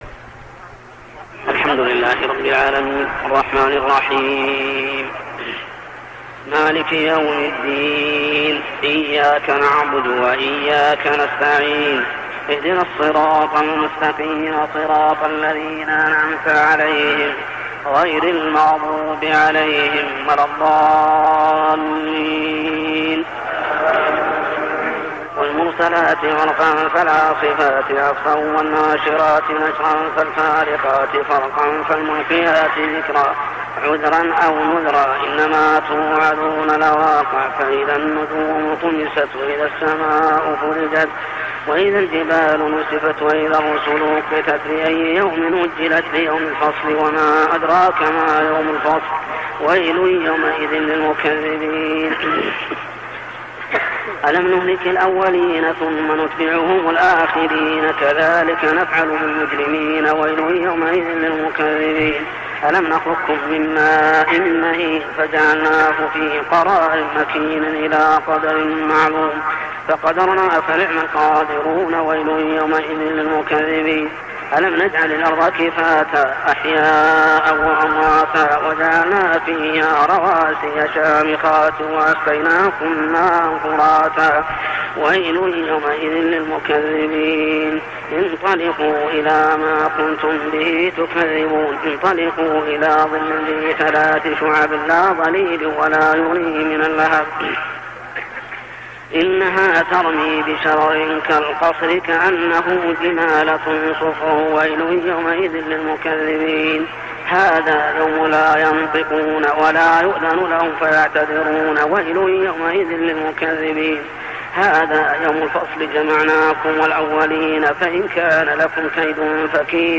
صلاة التراويح عام 1400هـ من سورة المرسلات كاملة حتى سورة الغاشية كاملة | Tarawih prayer from Surah Al-mursalat to surah Al-Ghashiyah > تراويح الحرم المكي عام 1400 🕋 > التراويح - تلاوات الحرمين